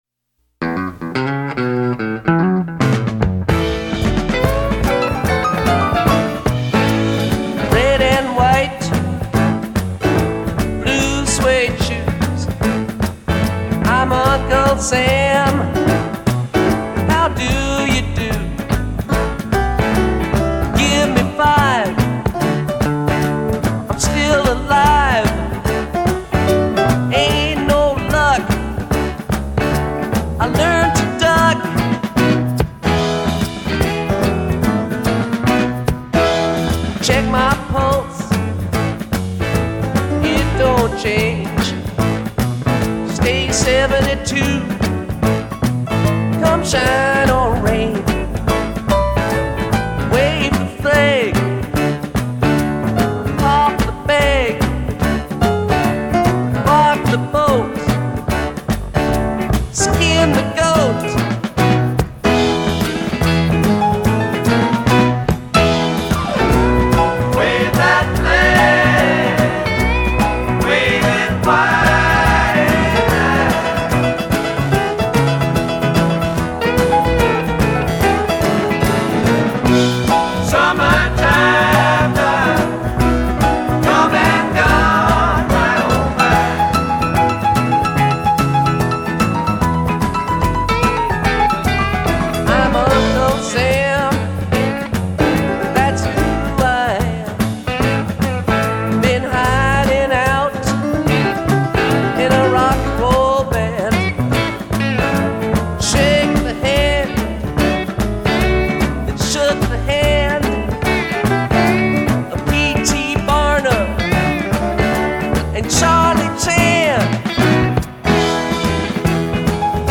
It's still rockin me.